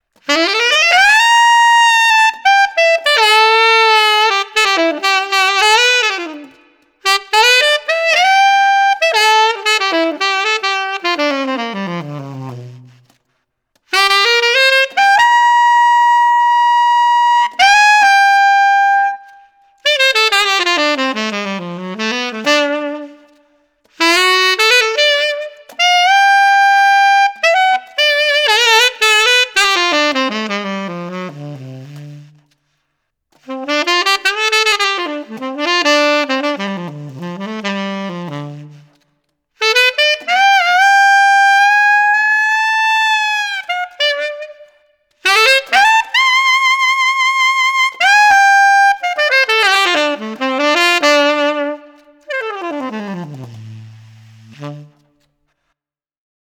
The second sound clip is of some altissimo playing with some reverb added so you can hear how the mouthpiece sounds in that range of the saxophone with reverb.
JS Custom Nova 7* Tenor Saxophone Mouthpiece – Altissimo with Reverb Added – Rigotti Gold 3 Light Reed